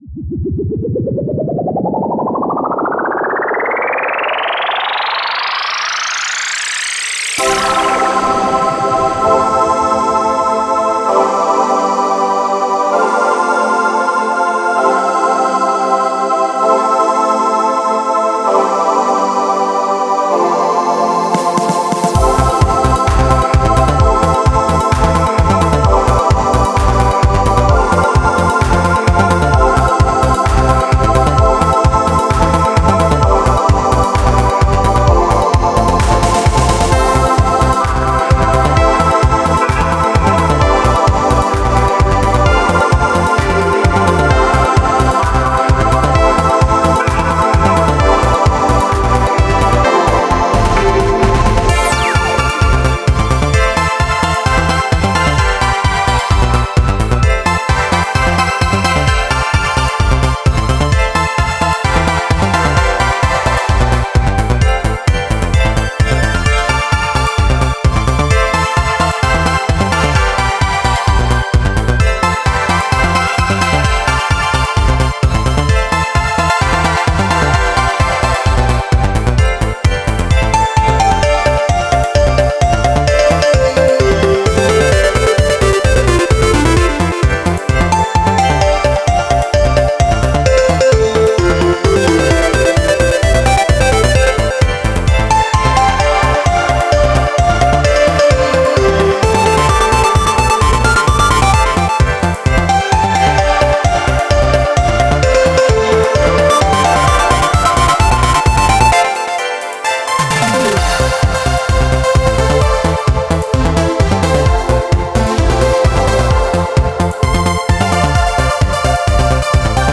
Сэмплы: свои + Вокодер